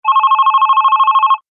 phone-incoming-call.mp3